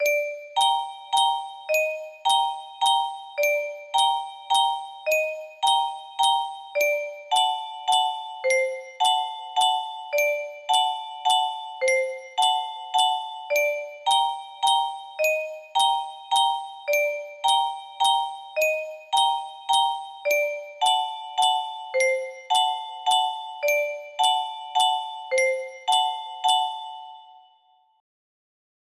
The Nightcaster's Carousel music box melody